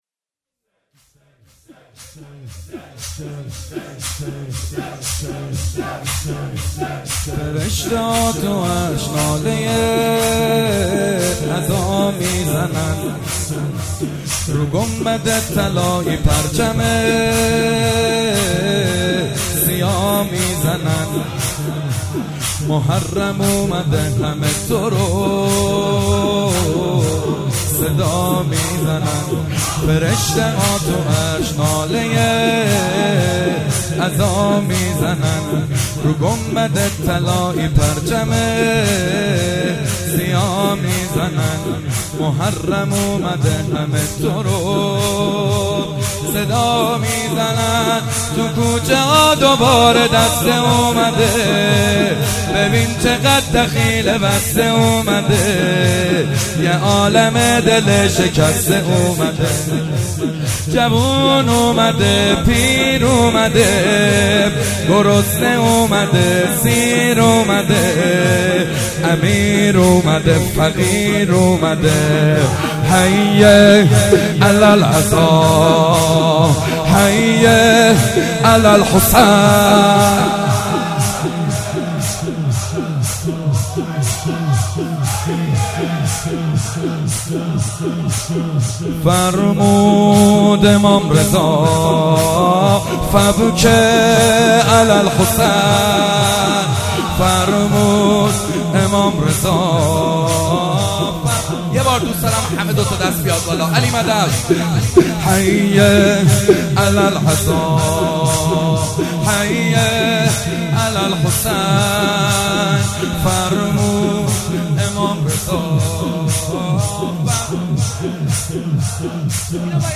صوت مداحی جدید